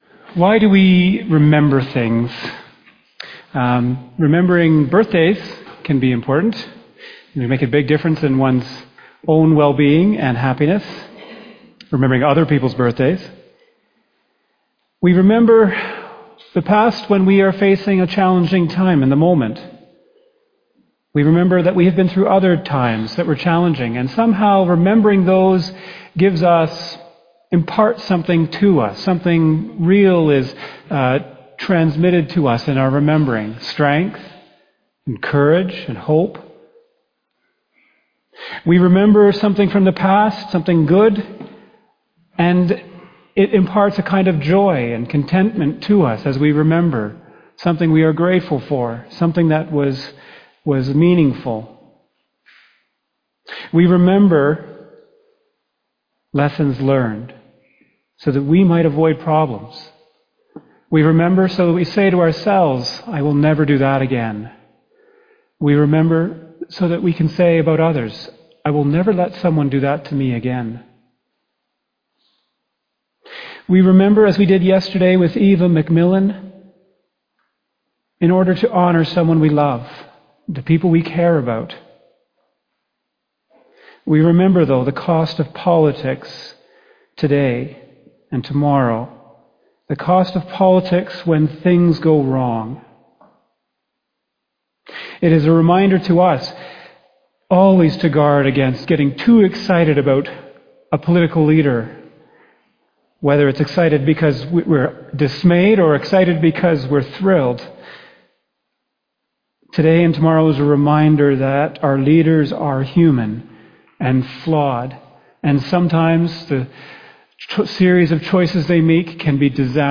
2024 Sermon November 10 2024